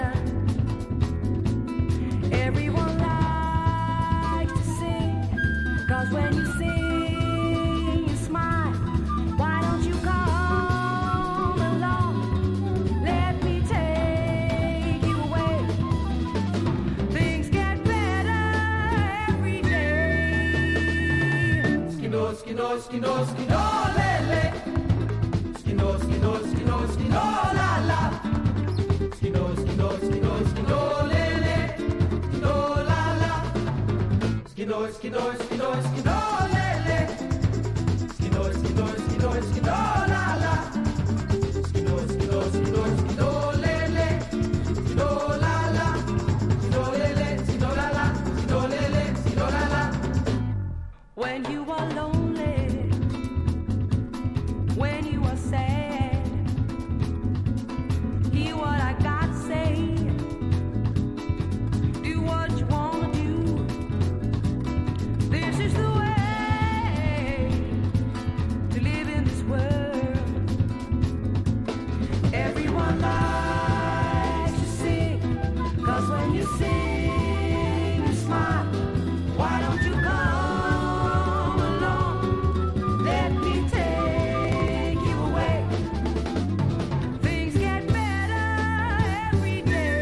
スウェーデンの6人組ブラジリアンバンドの生演奏によるクラブジャズ盤！
わずかにサウダージを香らせながらフロアユースなボッサ～サンバ～フュージョンを展開。